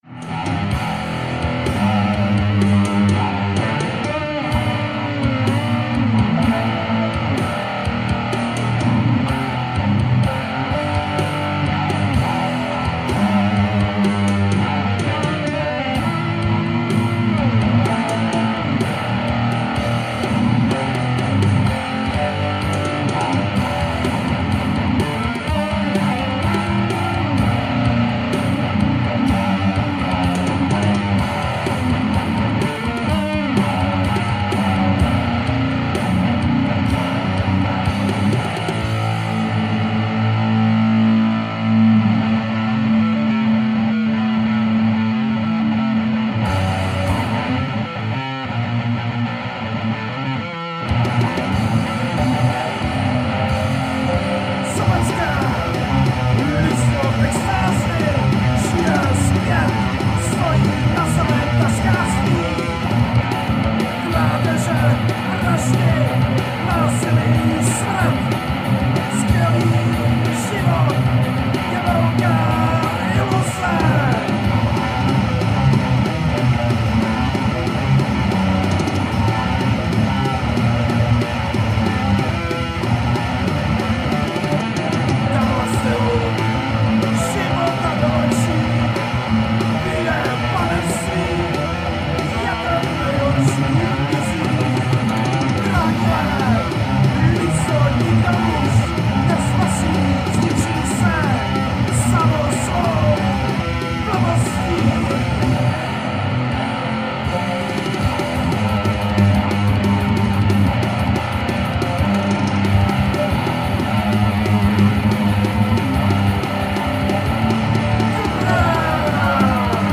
Žánr: Metal/HC
Old Thrash Power Metal